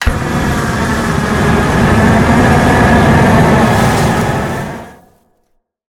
flamethrower_shot_03.wav